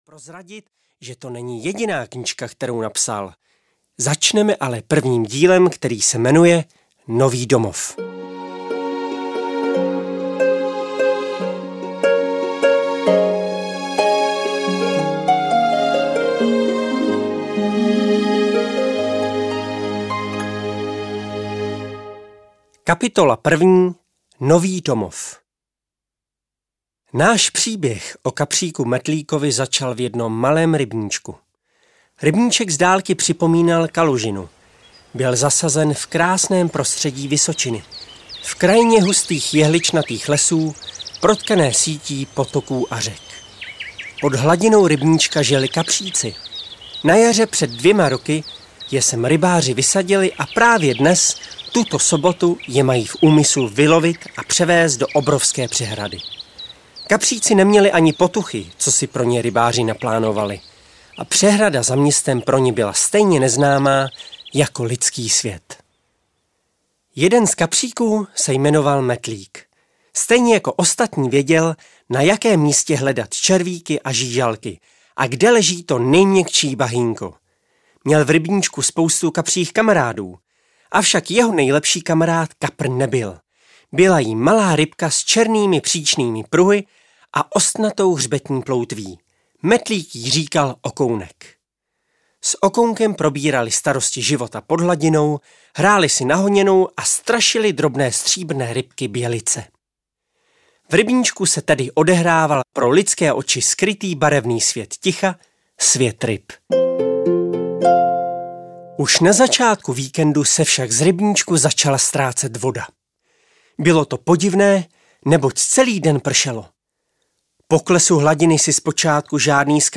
Dlouho očekávaná audiokniha prvního dílu Kapříka Metlíka namluvená Jakubem Vágnerem. Ponořte se pod hladinu a nechte se unést pohádkovým příběhem malého kapříka, kouzelnými melodiemi a samozřejmě nezapomenutelným hlasem Jakuba Vágnera.
Ukázka z knihy